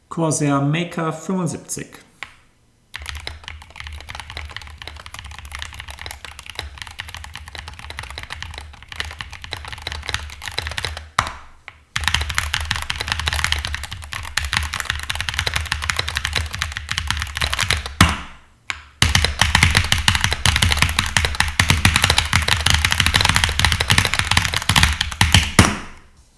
Sie verringern Resonanzen und lassen den Anschlag etwas deutlicher hervorstechen.
Im Ergebnis klackert die Makr 75 trocken und relativ tieffrequent, insgesamt hörbar, aber angenehm.
Insgesamt hebt sie sich durch ihren klaren Klang akustisch annehmbar von Kunststoff-Gehäusen der unteren Preisklassen ab.